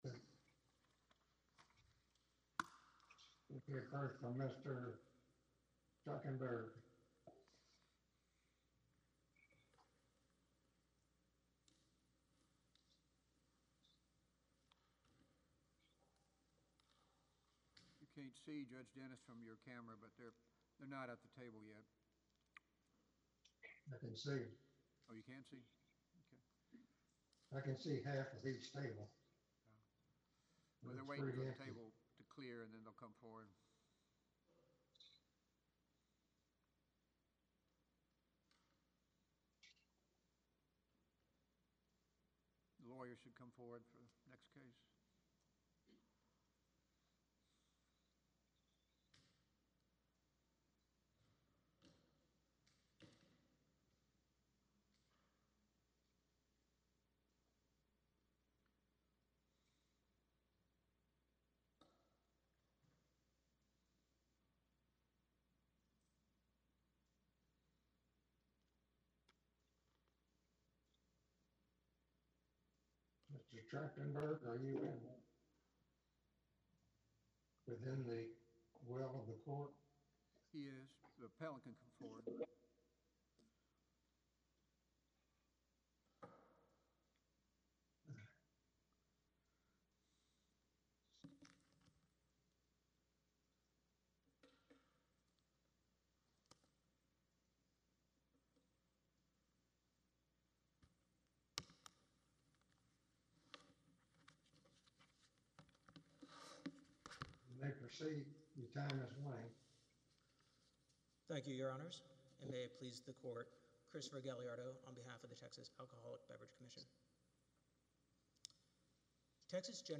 For those looking to keep score in these cases regarding how oral argument went and how the opinion ultimately turned out, here is the audio from the oral argument in the matter .